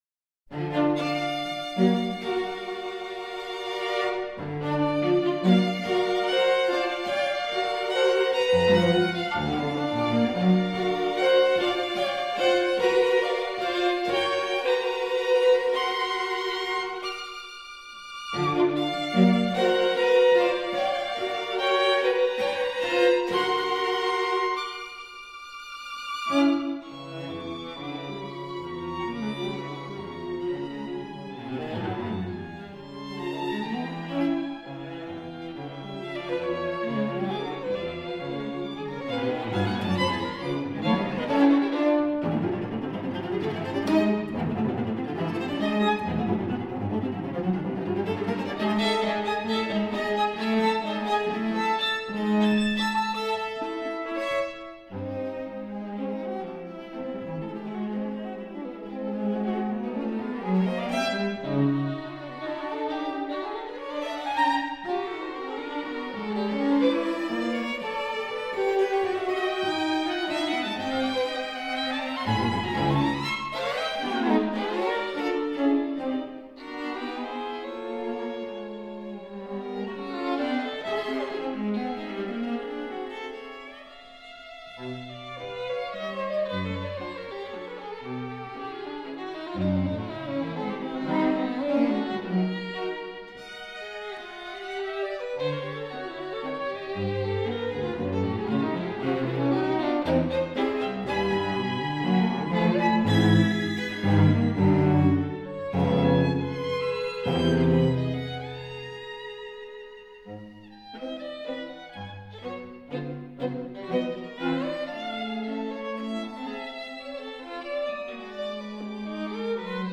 FAST (5:35)